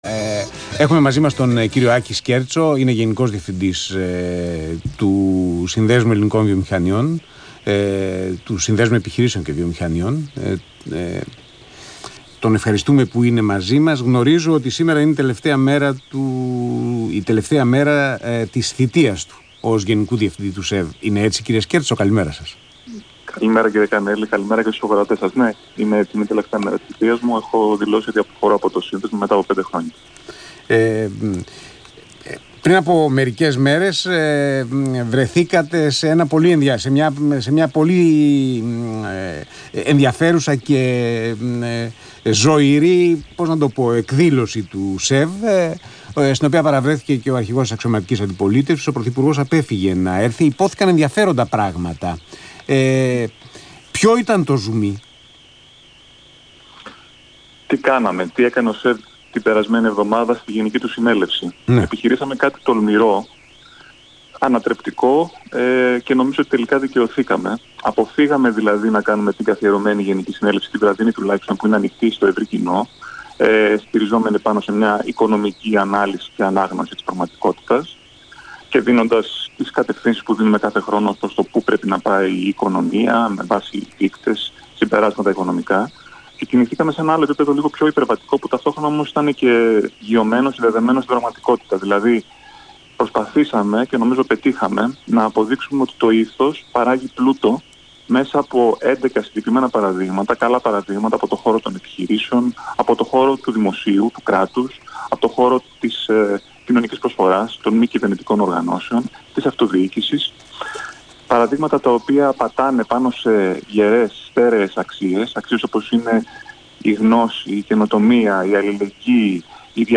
Συνέντευξη του Γενικού Διευθυντή του ΣΕΒ, κ. Άκη Σκέρτσου στον Ρ/Σ ΑΘΗΝΑ 9.84, 28/6/2019